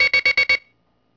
ブザー連続音・断続音の２音を吹鳴可能です。
(▲2)   連続音
断続音